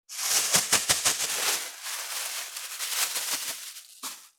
633コンビニ袋,ゴミ袋,スーパーの袋,袋,買い出しの音,ゴミ出しの音,袋を運ぶ音,
効果音